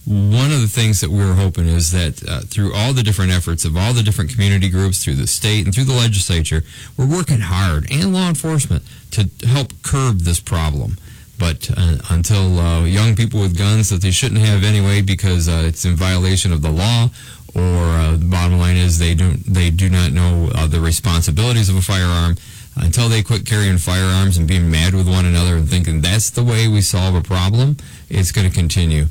Kalamazoo County Sheriff Rick Fuller says gun violence needs to stop.